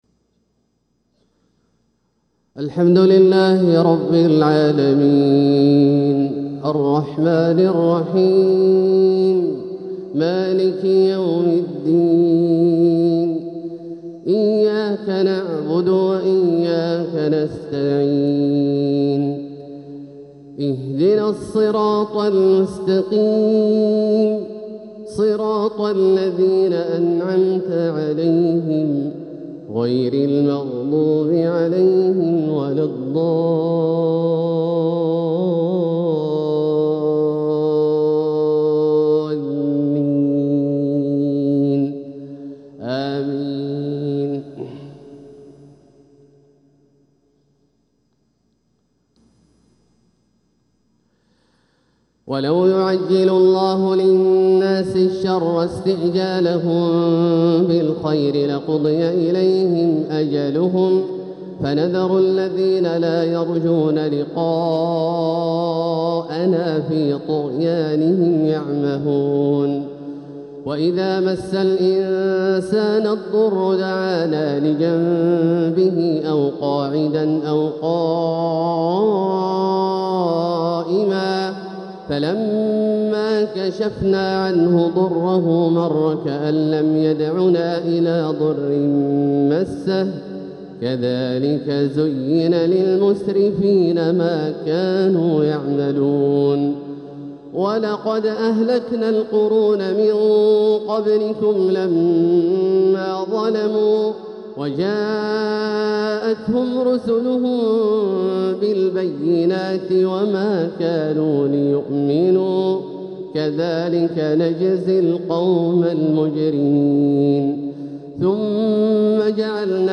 تلاوة من سورة يونس 11-25 | فجر الثلاثاء 3 ربيع الأول 1447هـ > ١٤٤٧هـ > الفروض - تلاوات عبدالله الجهني